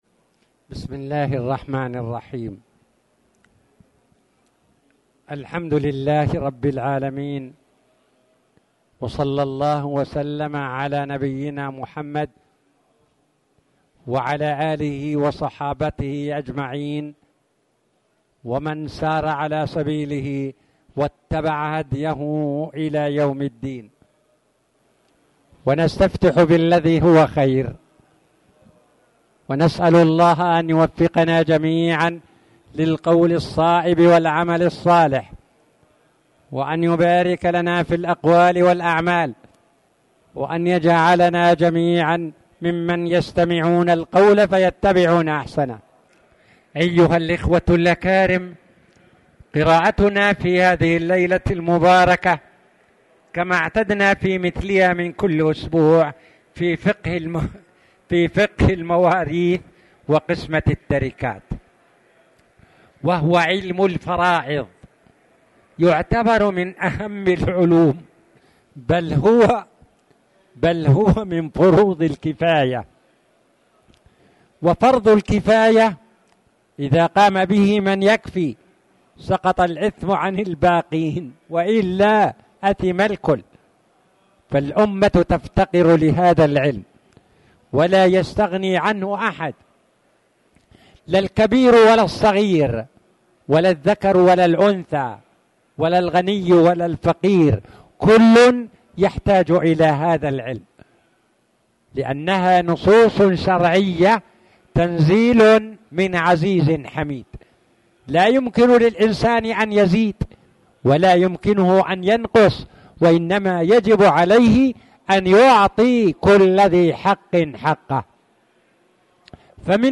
تاريخ النشر ٢١ صفر ١٤٣٨ هـ المكان: المسجد الحرام الشيخ